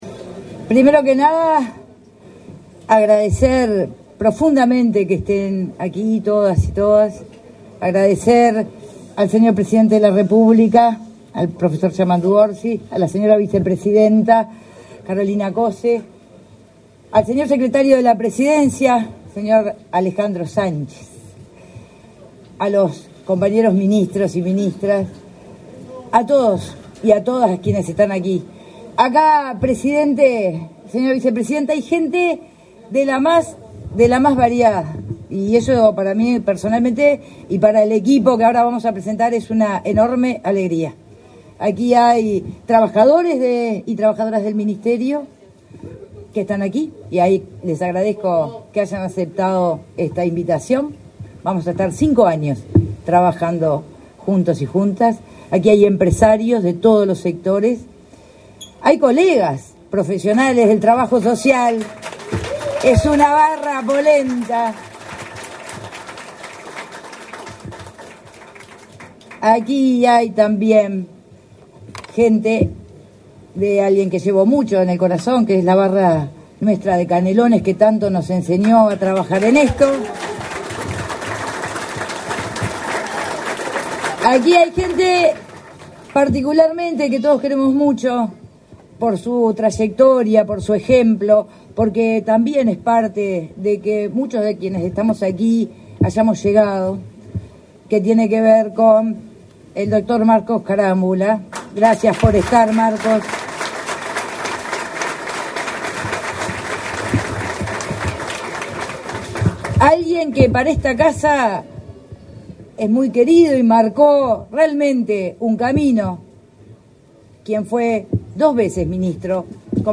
Palabras de la ministra de Transporte y Obras Públicas, Lucía Etcheverry
El presidente de la República, profesor Yamandú Orsi, y la vicepresidenta, Carolina Cosse, participaron, este 7 de marzo, en la asunción de las